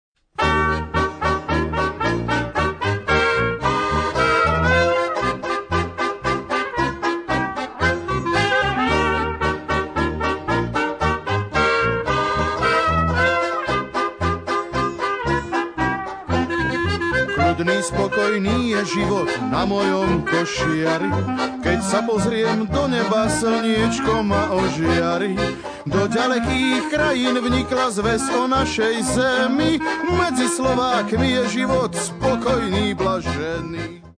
husle, trúbka, spev
klarinet, spev
banjo, drumbľa, spev
akordeón, spev
tuba, spev
Nahrané a zostrihané analógovou technikou.